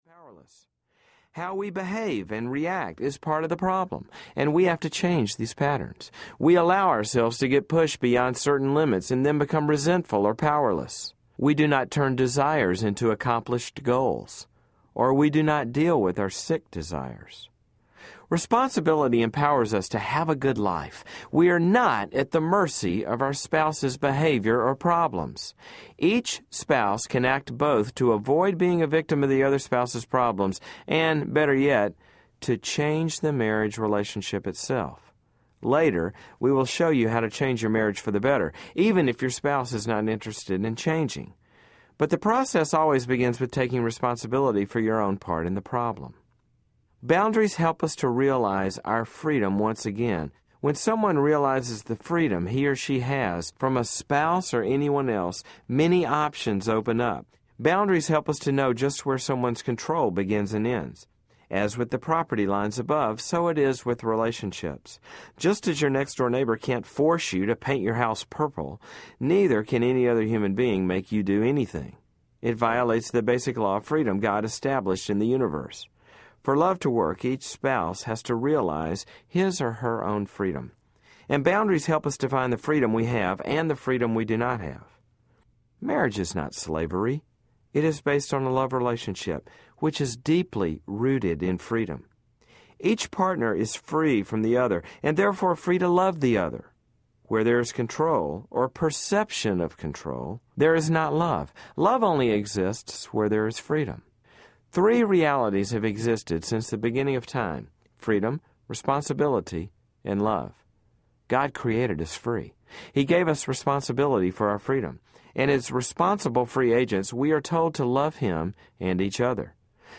Boundaries in Marriage Audiobook
3 Hrs. – Abridged